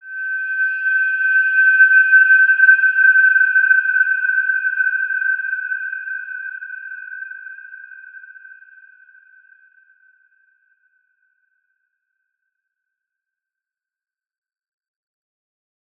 Wide-Dimension-G5-p.wav